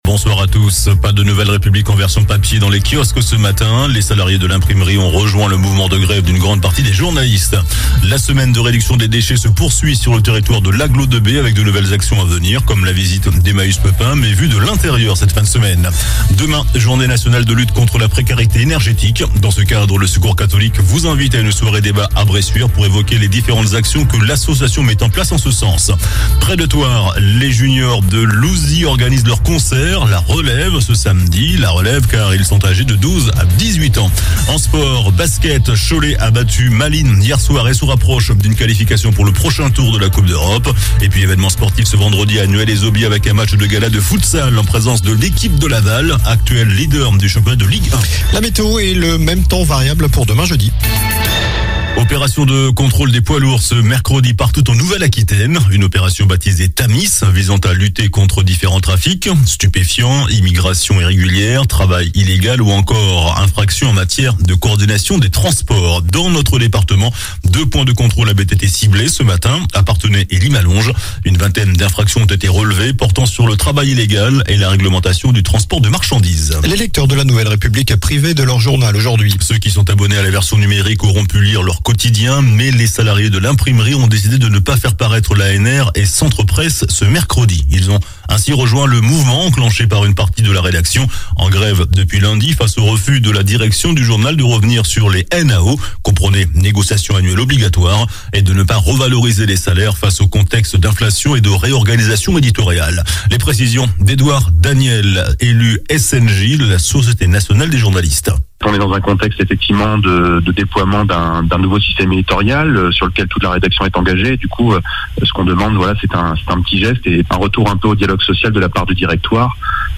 JOURNAL DU MERCREDI 23 NOVEMBRE ( SOIR )